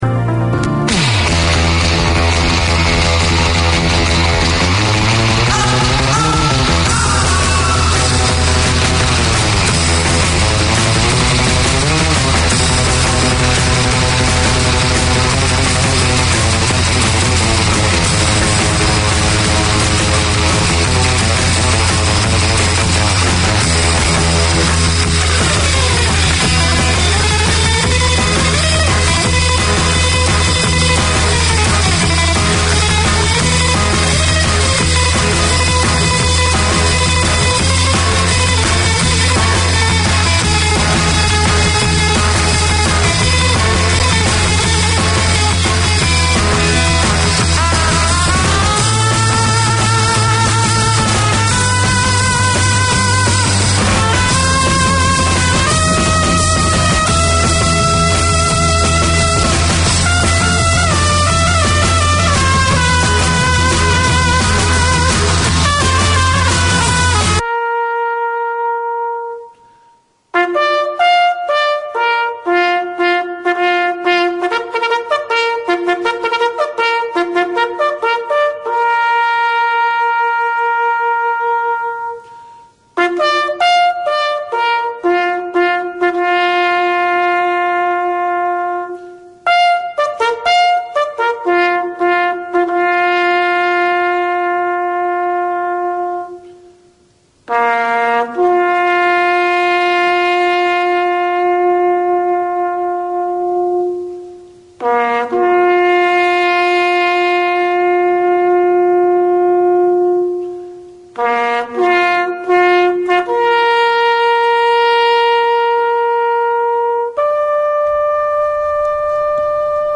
Pasifika Wire 4:50pm SATURDAY Community magazine Language: English Pasifika Wire Live is a talanoa/chat show featuring people and topics of interest to Pasifika and the wider community.